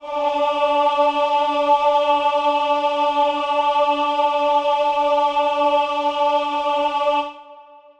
Choir Piano
D4.wav